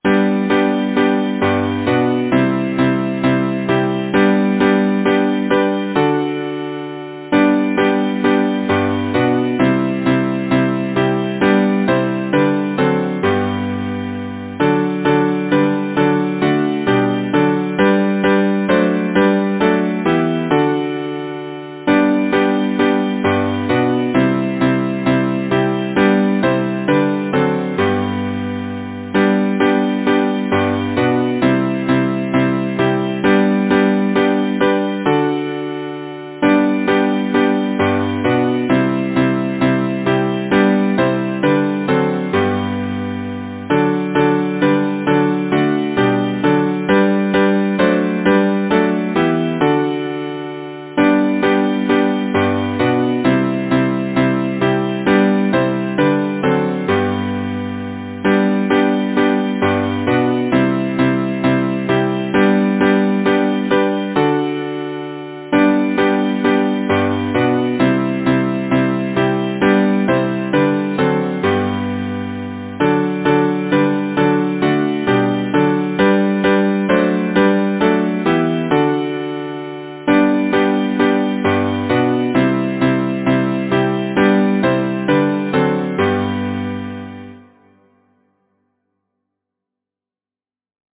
Title: Gentle words Composer: Cyrus Cornelius Pratt Lyricist: Number of voices: 4vv Voicing: SATB Genre: Secular, Partsong
Language: English Instruments: A cappella